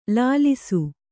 ♪ lālisu